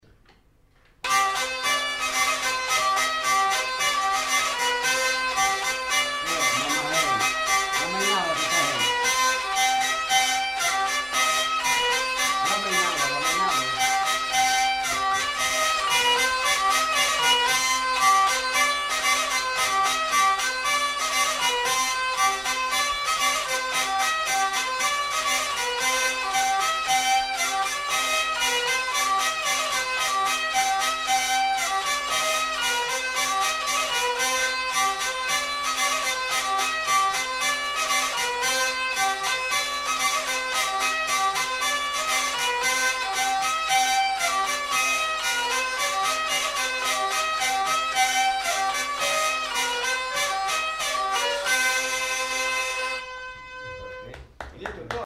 Lieu : Pyrénées-Atlantiques
Genre : morceau instrumental
Instrument de musique : vielle à roue
Danse : polka